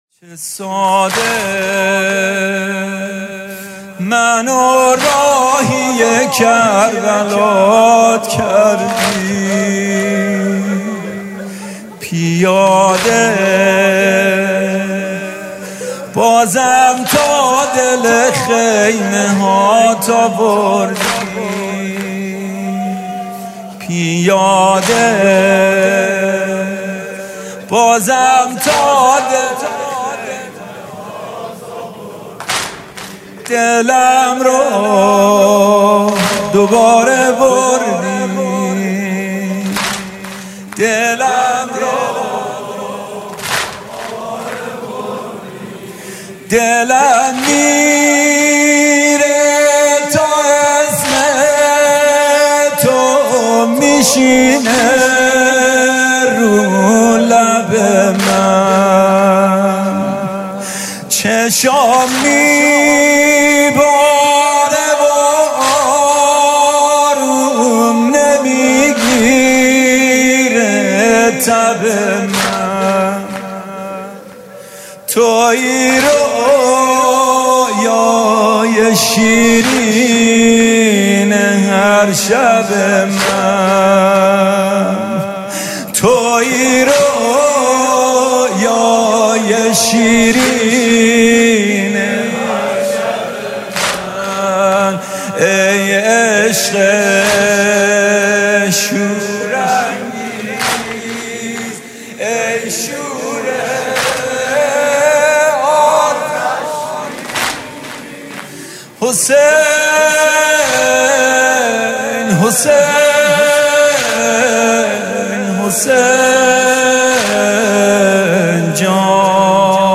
music-icon واحد: چه ساده منو راهی کربلات کردی